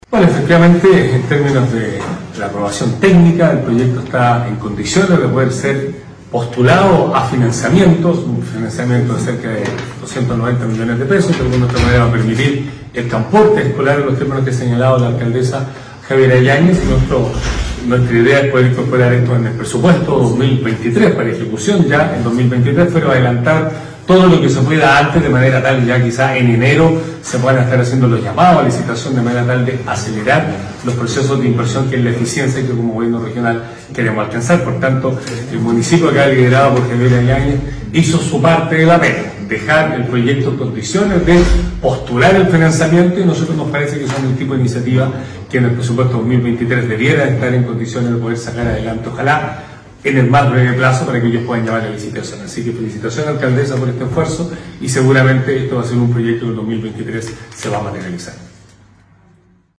En ese marco, el Gobernador Patricio Vallespín indicó que tras el visto bueno técnico de parte de la División de Infraestructura y Transportes del Gore Los Lagos, la iniciativa está en condiciones de ser postulada a financiamiento:
GOBERNADOR-BUSES-.mp3